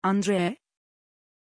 Pronunciación de Andrée
pronunciation-andrée-tr.mp3